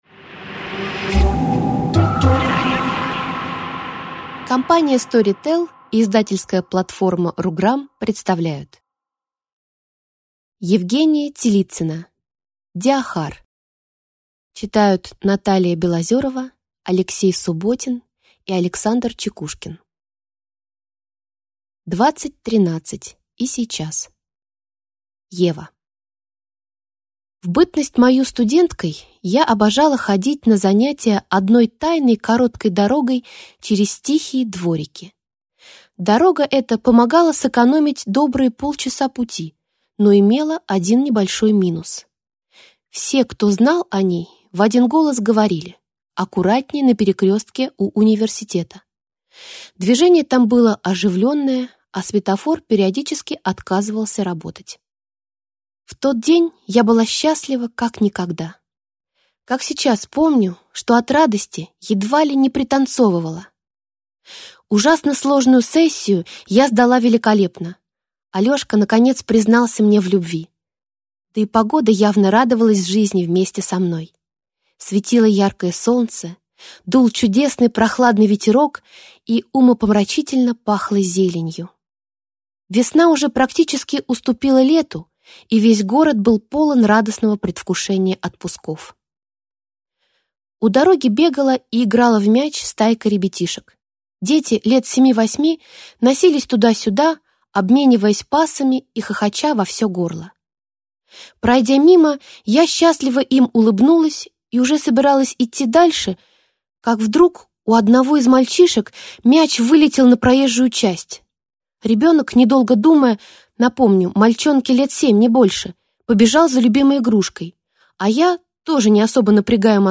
Аудиокнига Диахар | Библиотека аудиокниг